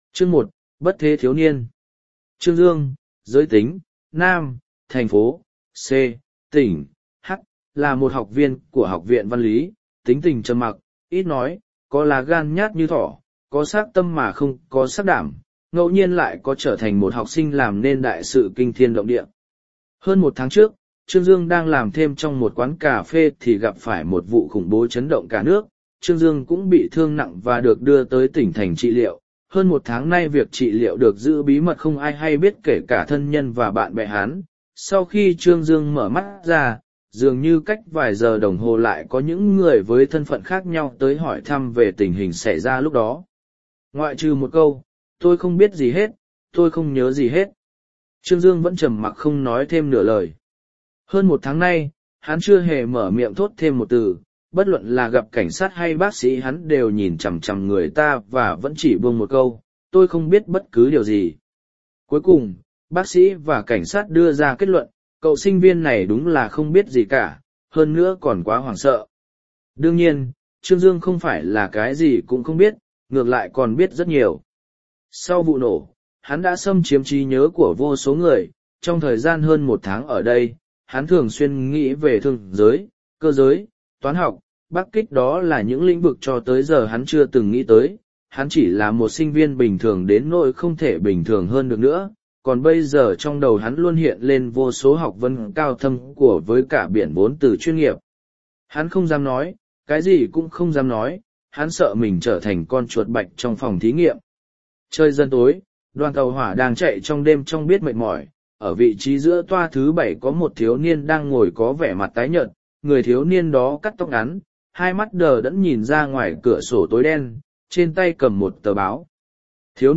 Đồ Thần Chi Lộ Audio - Nghe đọc Truyện Audio Online Hay Trên TH AUDIO TRUYỆN FULL